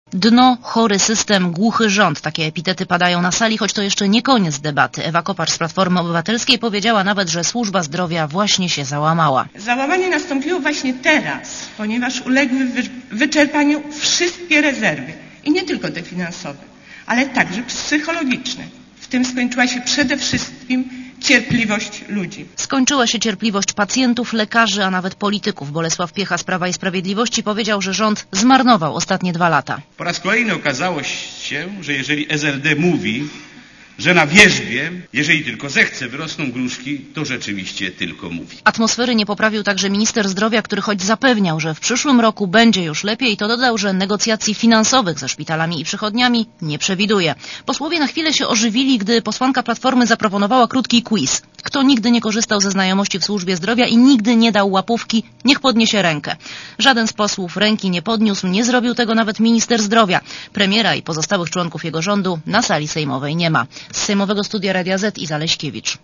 Relacja reporterki Radia Zet (260Kb)